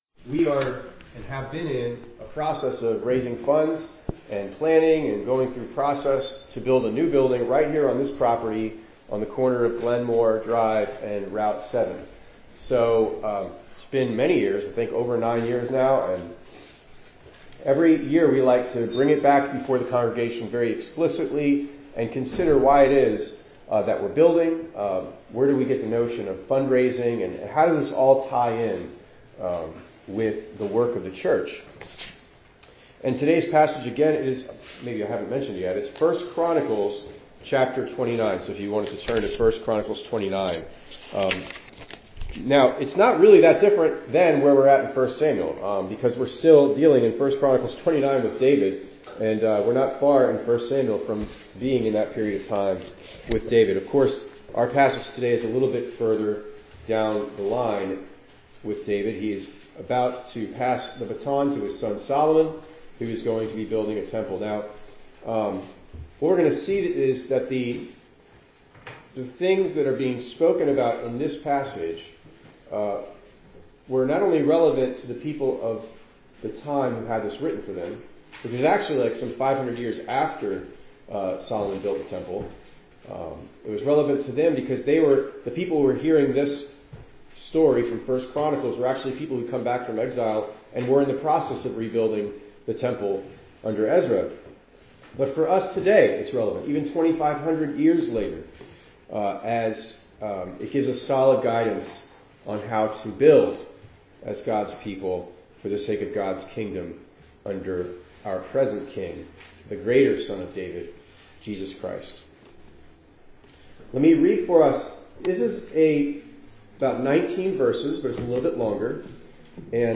2_9_25_ENG_Sermon.mp3